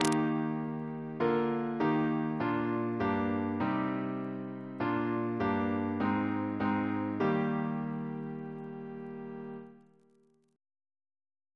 Single chant in E♭ Composer: C. Hylton Stewart (1884-1932), Organist of Rochester and Chester Cathedrals, and St. George's, Windsor